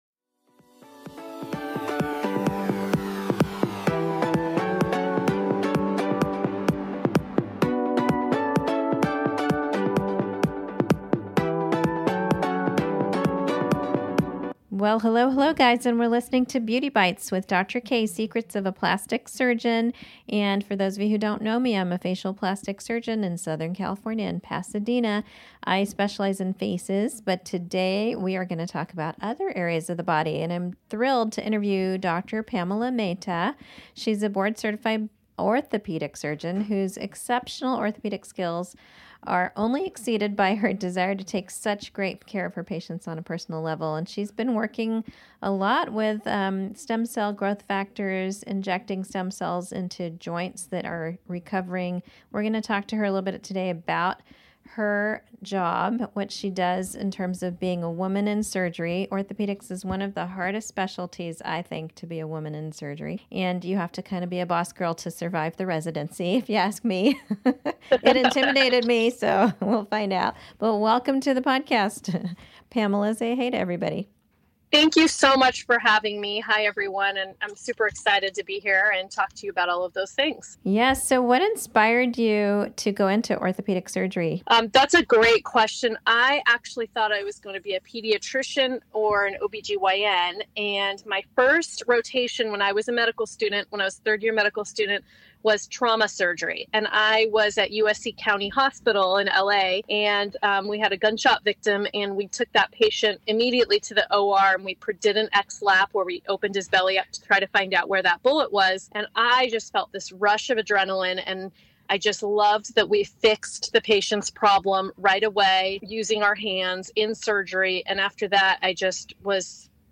I’m joined via FaceTime by board certified orthopedic surgeon